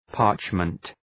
Προφορά
{‘pɑ:rtʃmənt} (Ουσιαστικό) ● περγαμηνή